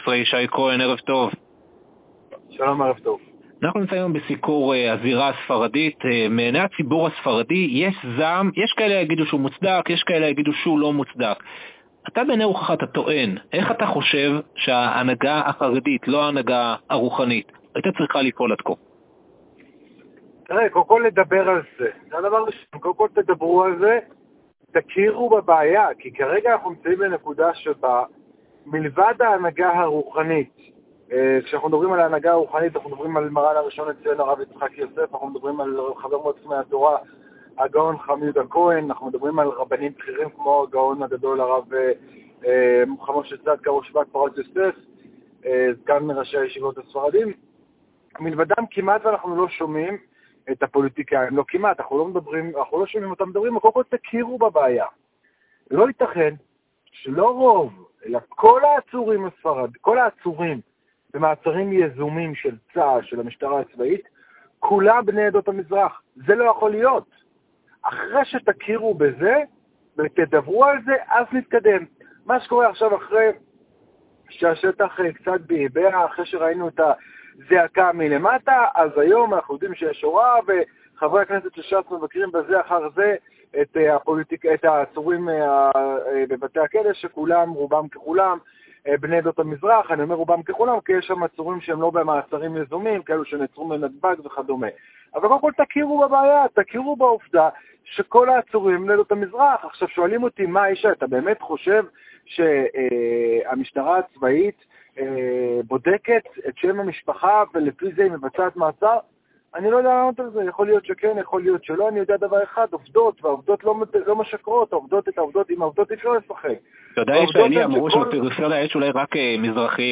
גזירת הגיוס: למה רק עוצרים רק בחורים ספרדים??? | ראיון מרתק